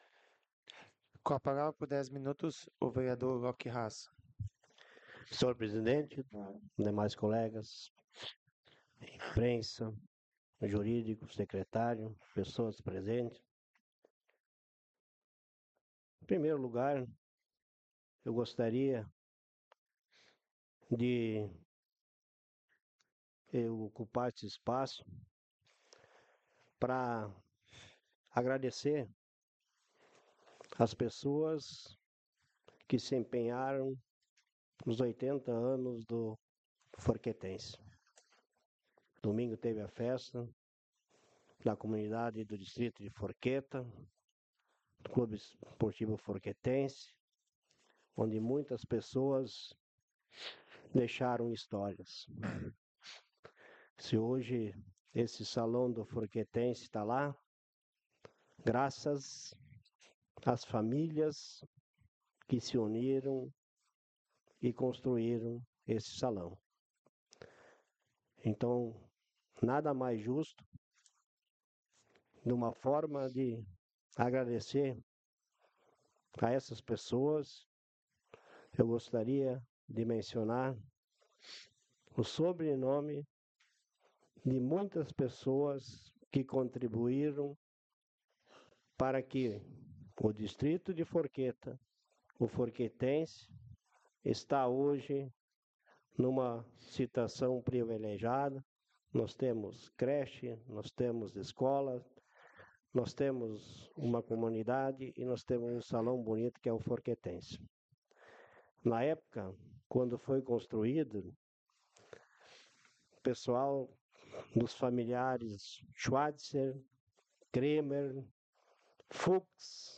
Áudio das Sessões Vereadores